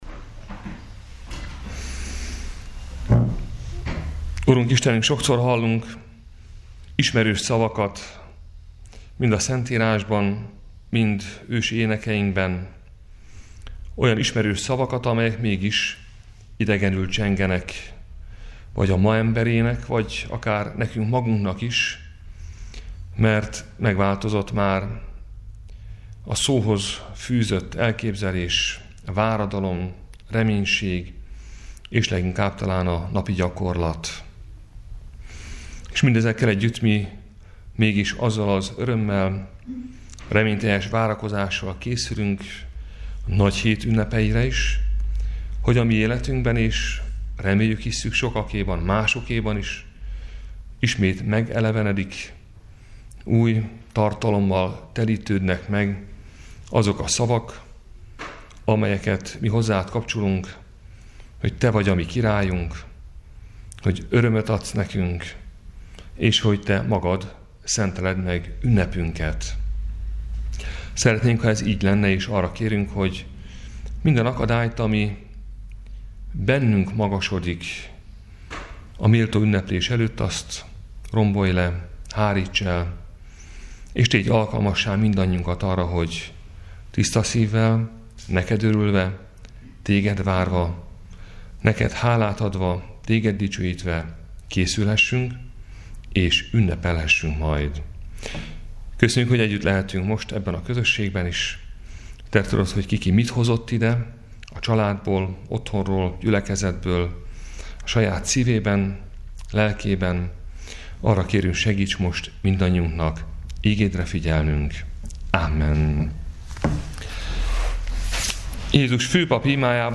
Áhítat, 2019. április 10.
Áhítatok a püspöki hivatalban 2019. április 10.